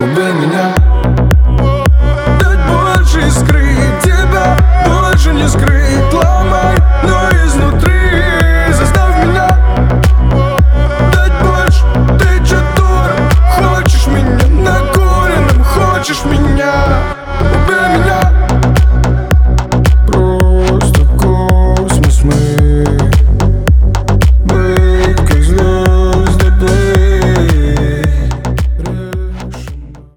• Качество: 320, Stereo
deep house
атмосферные
мрачные